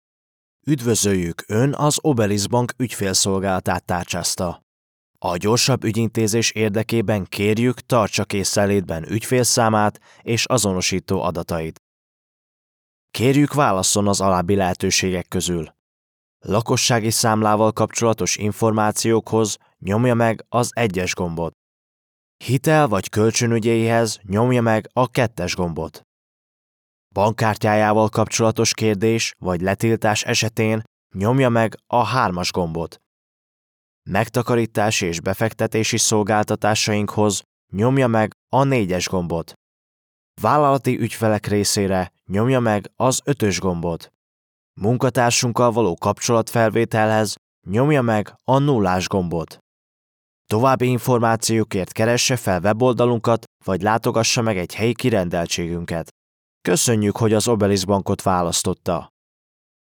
Male
Adult (30-50)
My voice is warm, relaxed, clear and versatile, with a natural mid-to-deep tone that works perfectly for commercials, corporate narration, e-learning, audiobooks and character work.
Phone Greetings / On Hold
Professional Ivr Demo
All our voice actors have professional broadcast quality recording studios.
1002HungarianPhoneSystemDemo.mp3